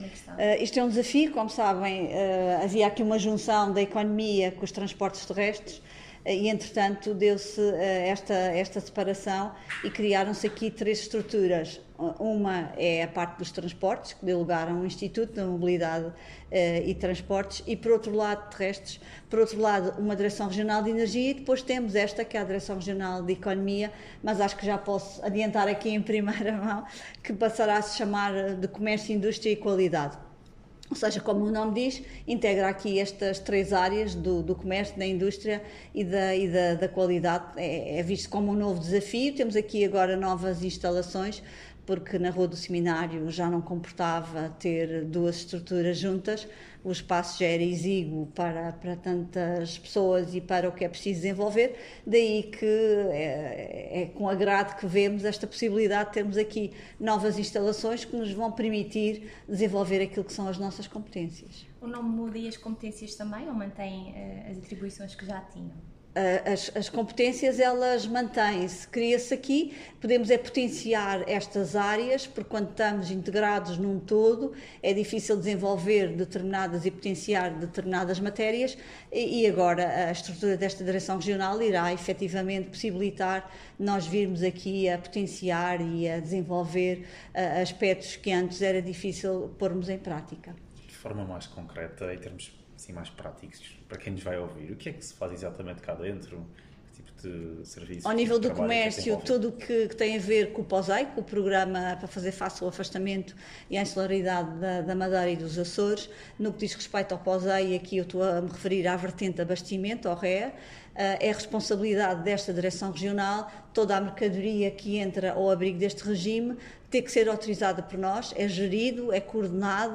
A garantia foi dada, esta tarde, pelo Secretário Regional da Economia, durante a visita às instalações da Direção Regional da Economia, liderada por Isabel Catarina Rodrigues, num momento que teve como principal objetivo cumprimentar os colaboradores sob a sua tutela e apresentar formalmente a equipa do seu gabinete, num gesto simbólico de proximidade e reconhecimento.